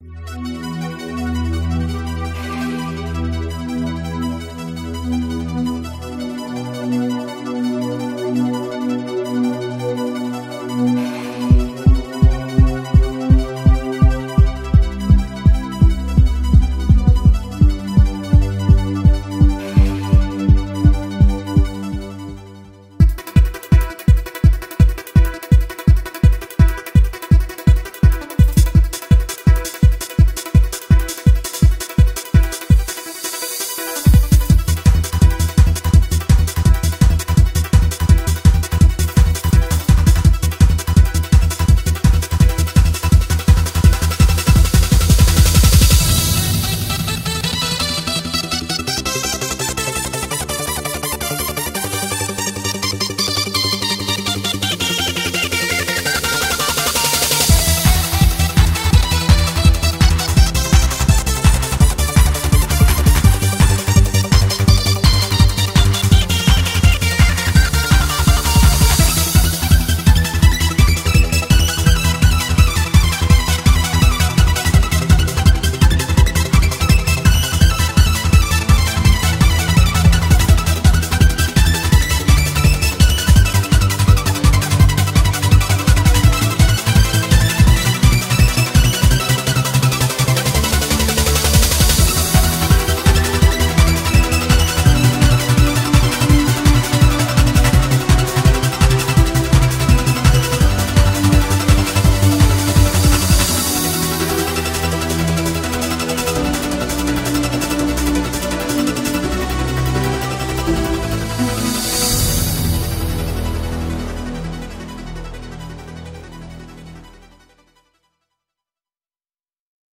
BPM167
Audio QualityPerfect (High Quality)
Comments[TRANCE]